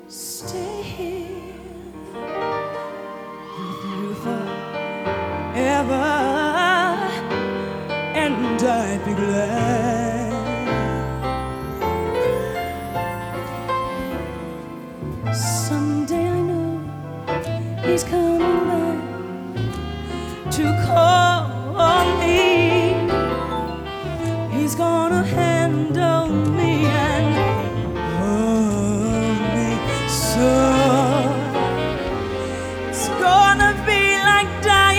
Жанр: R&B / Танцевальные / Соул